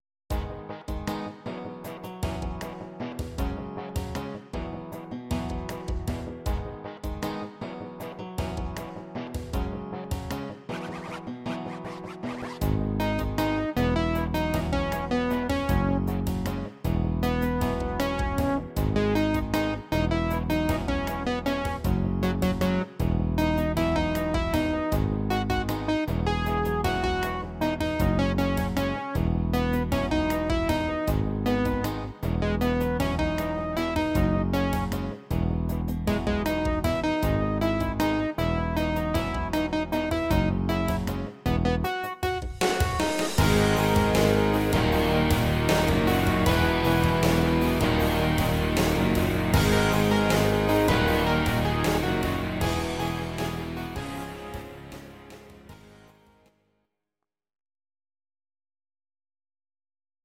These are MP3 versions of our MIDI file catalogue.
Your-Mix: Rock (2958)